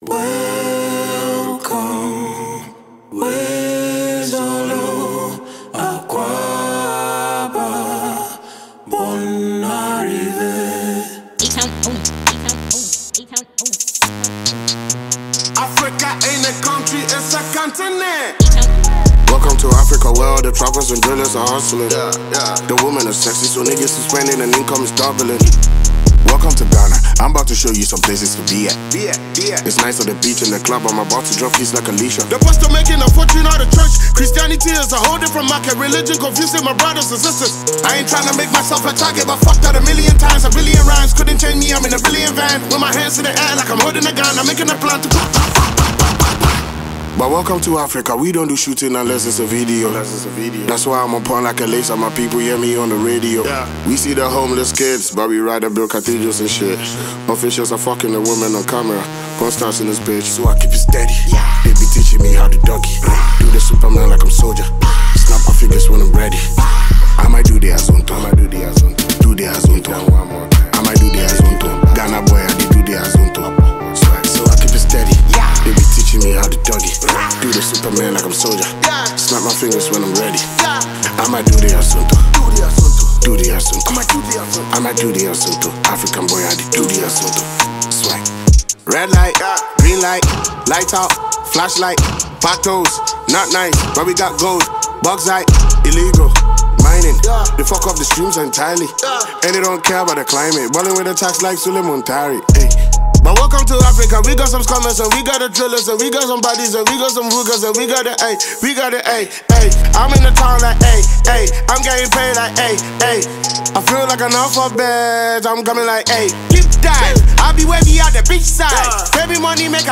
Ghanaian rap heavyweight